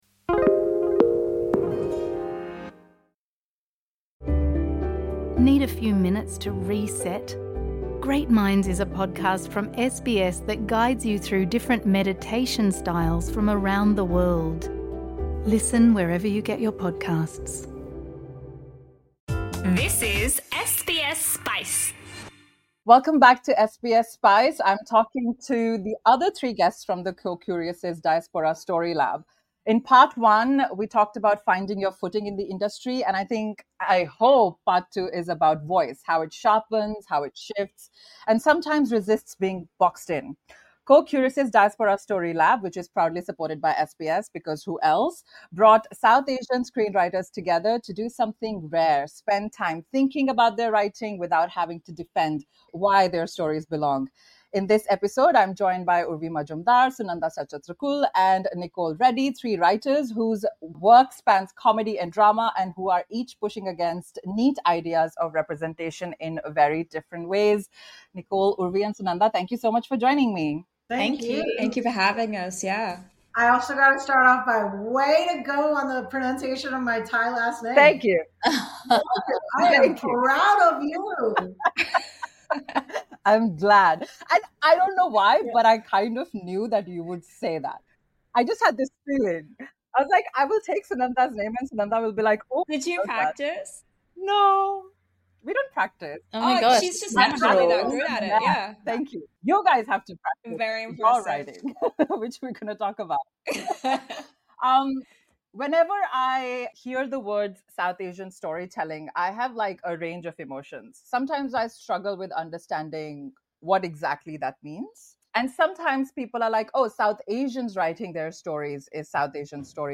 Writers talk honestly about power, risk and what it really takes to put brown characters on screen.